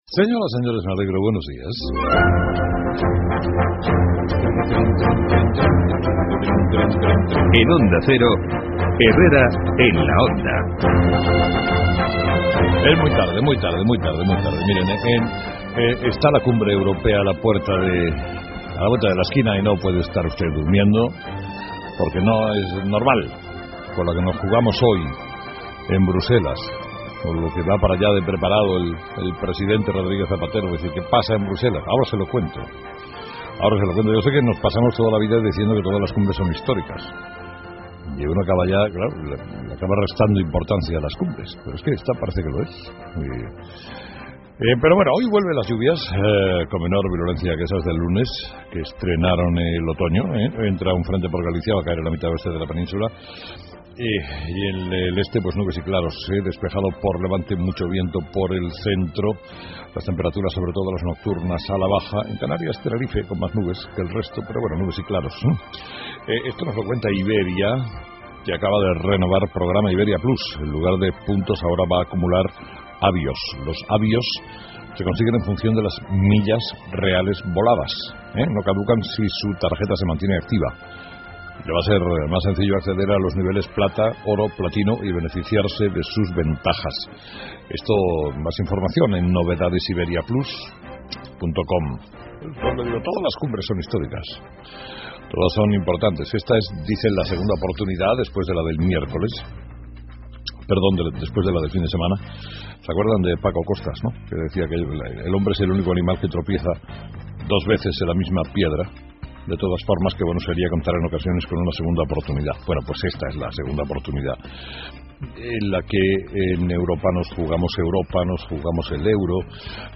Carlos Herrera habla en su editorial sobre la cumbre europea que tendrá lugar hoy en Bruselas y nos cuenta que "los líderes de los países van a tener que decidir: si se recapitaliza la banca, el fondo de rescate y cuánto se le quita a la deuda de Grecia, que no va a poder pagar".